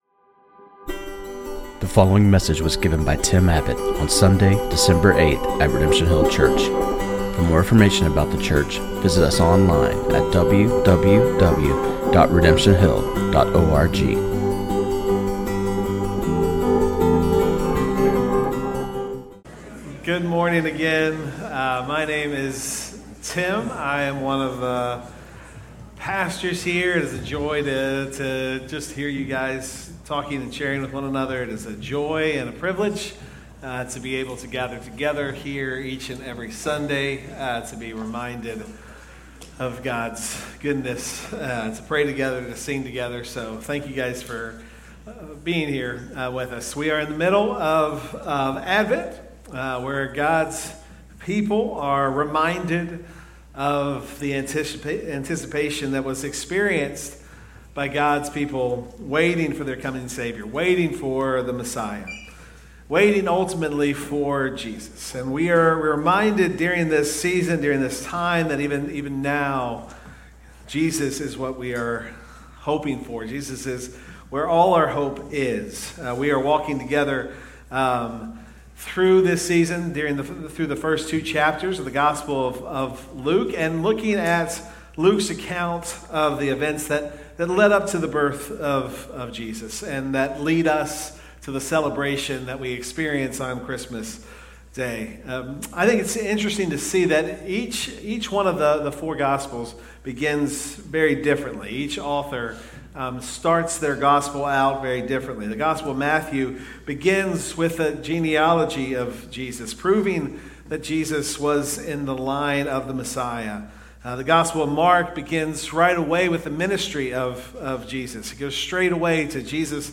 This sermon on Luke 1:46-56